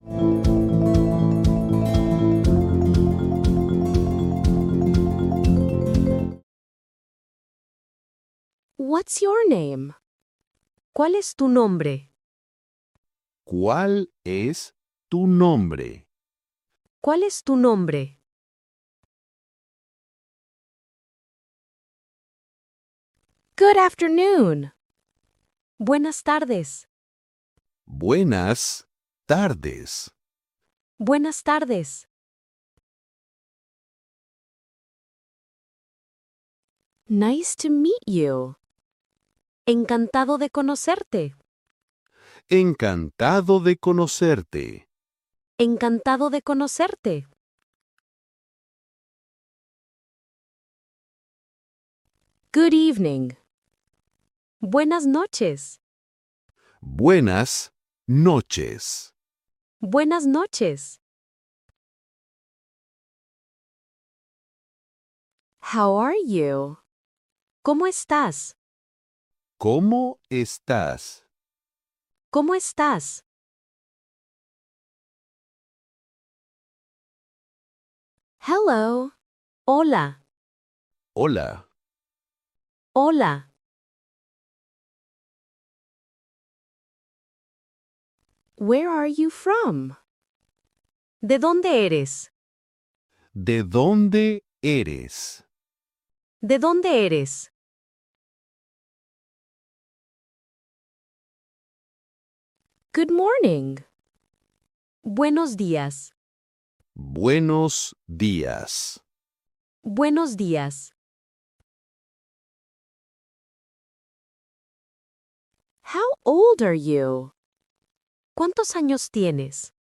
1 Hour Real Spanish Conversation for Beginners